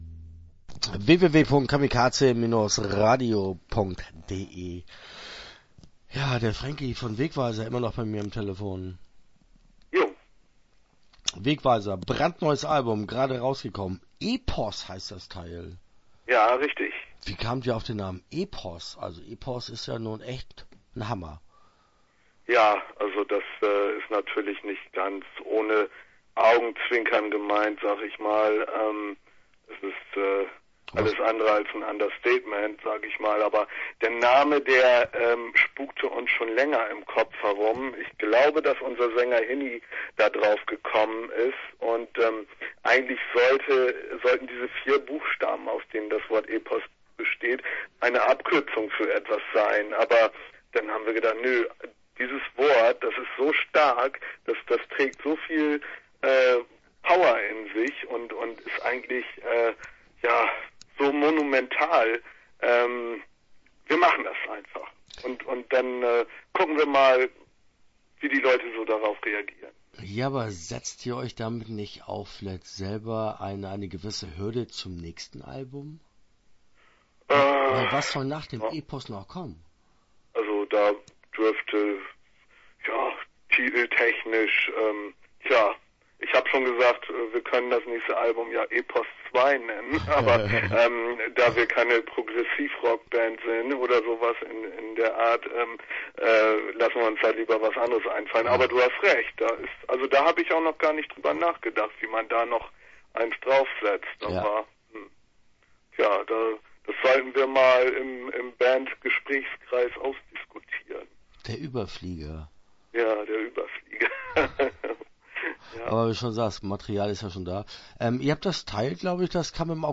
Wegweiser - Interview Teil 1 (9:09)